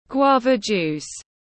Nước ổi ép tiếng anh gọi là guava juice, phiên âm tiếng anh đọc là /ˈɡwɑː.və ˌdʒuːs/
Guava juice /ˈɡwɑː.və ˌdʒuːs/